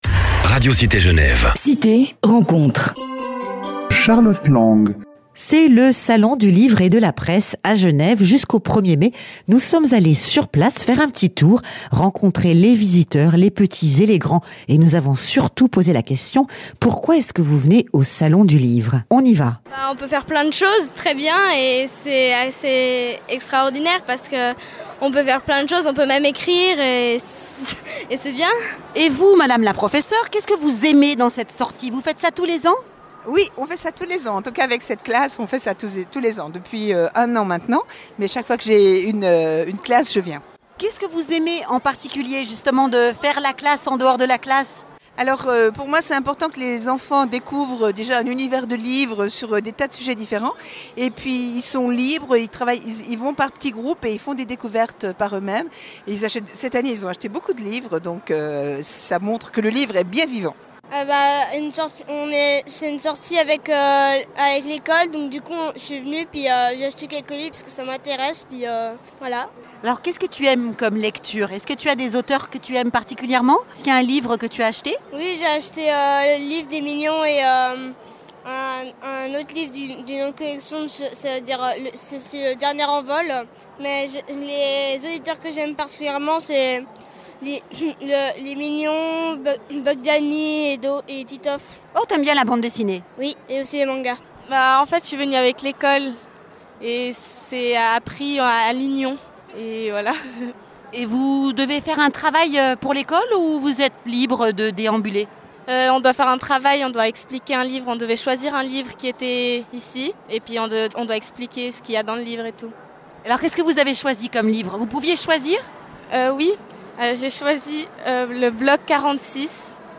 Radio Cité Genève: Reportage au 30ème Salon du Livre et de la Presse à Genève.